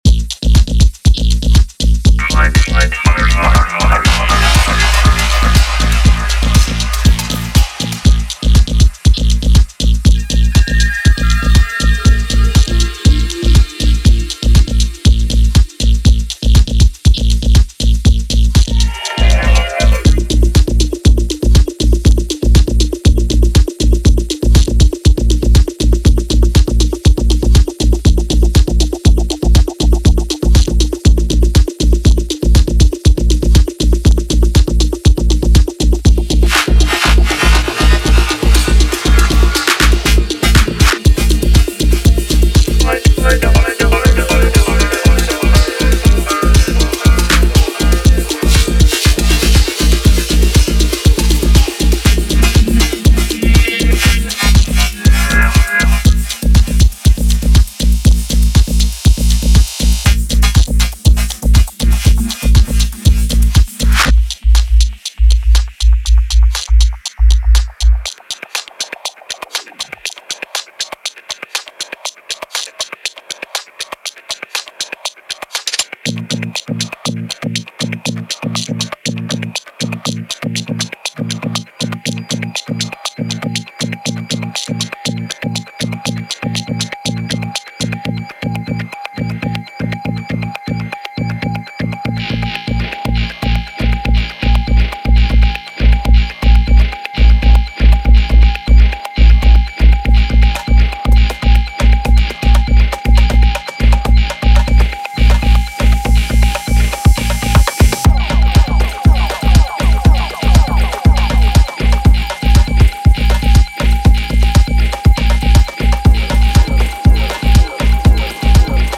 4 intricate signals for late-night movement.